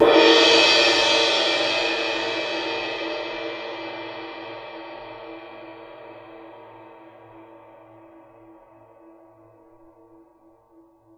susCymb1-hit_f_rr2.wav